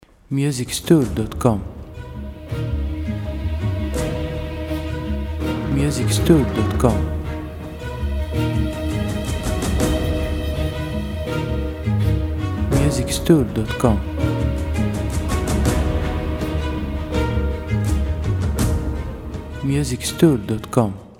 • Type : Instrumental
• Bpm : Moderato
• Genre : Action / Battle Soundtrack